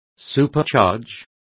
Complete with pronunciation of the translation of supercharges.